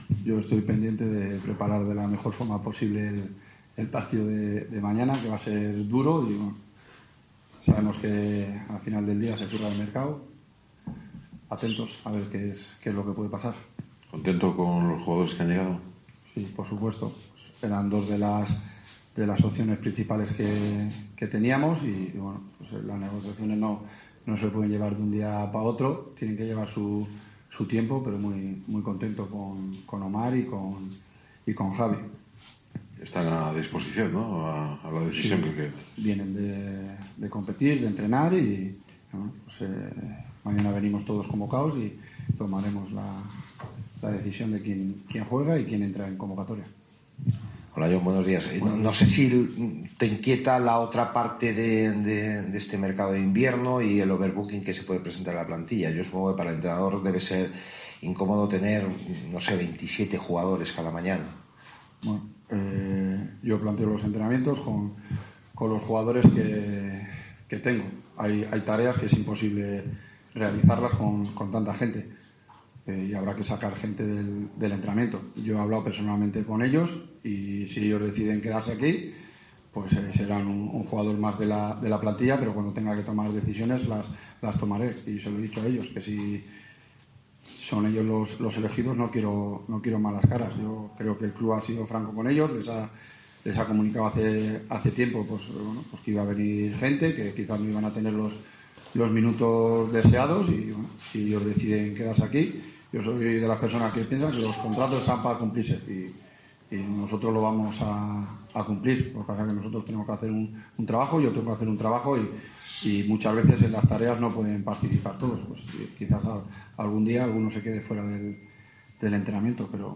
Escucha aquí las palabras del míster de la Deportiva Ponferradina, Jon Pérez Bolo